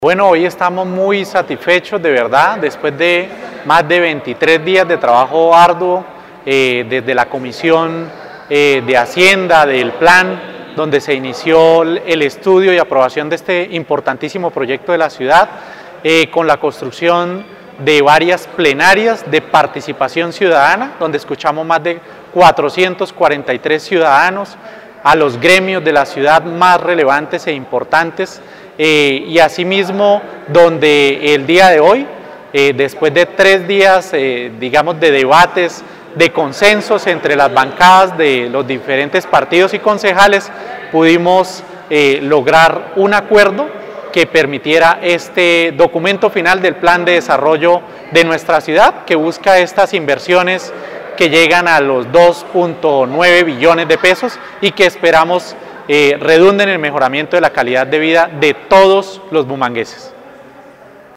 Francisco Javier González Gamboa, ponente del Proyecto de Acuerdo del Plan de Desarrollo 2020-2023